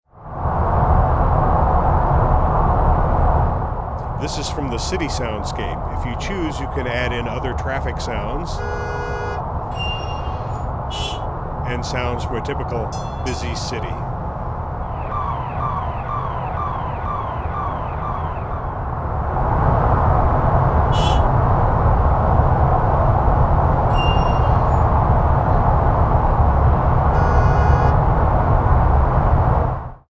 10 naturally recorded high quality digital SoundStories
Each SOUND+SLEEP SoundStory is recorded the hard way.
SOUND+SLEEP recordings are longer than any competing sound machine and have hundreds of enhancing sounds that are randomly and algorithmically mixed into the base sound story in real time.
city.mp3